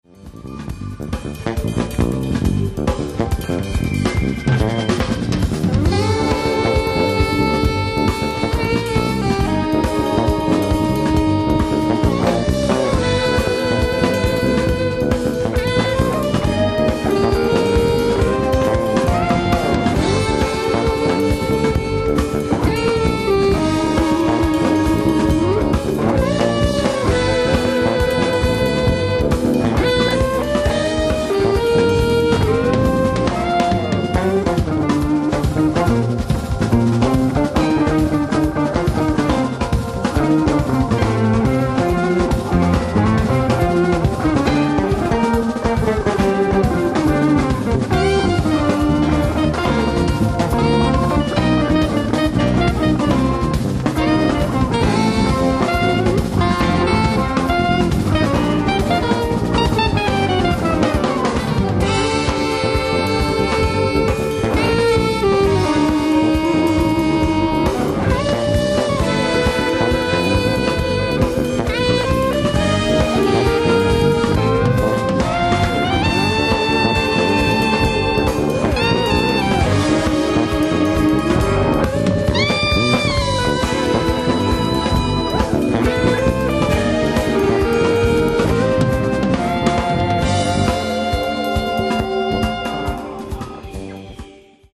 Disc 1 & 2：ライブ・アット・パリ、フランス 04/09/2000
※試聴用に実際より音質を落としています。